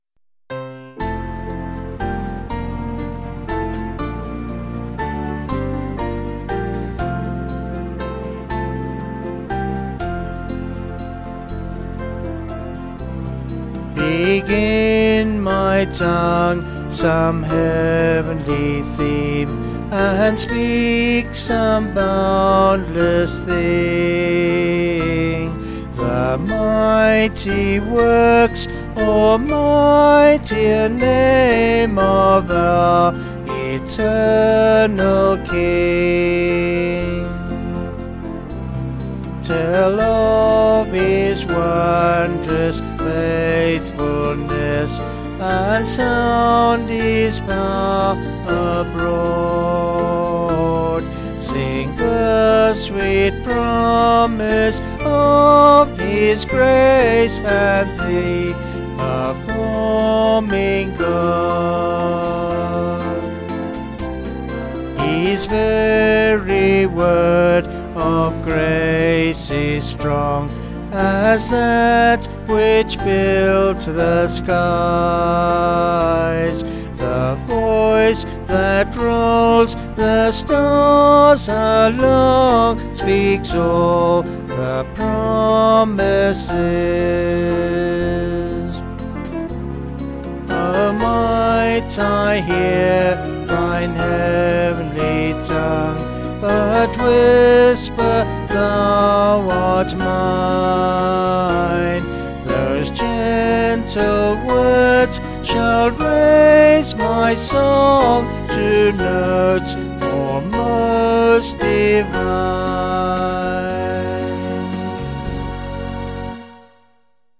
Vocals and Organ   263.9kb Sung Lyrics 1.5mb